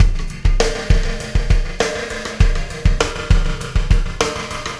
Pulsar Beat 17.wav